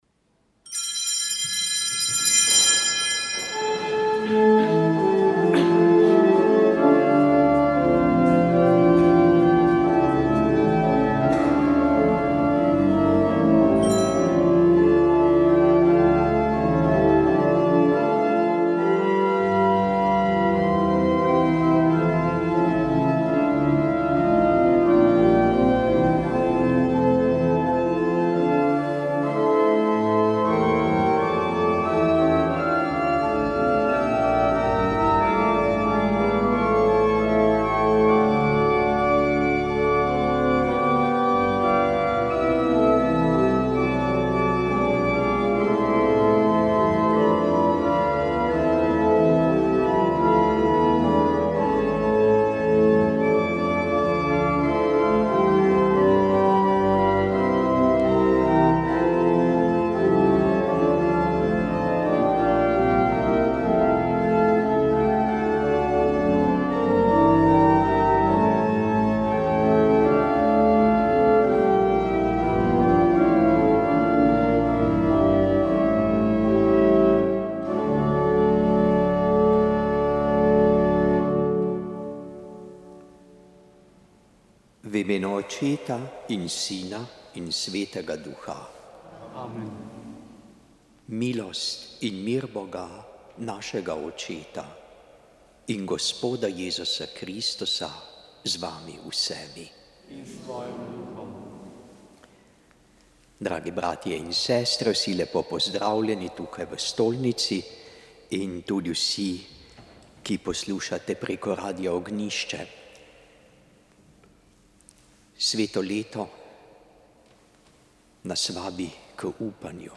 Sv. maša iz cerkve Marijinega oznanjenja na Tromostovju v Ljubljani 15. 10.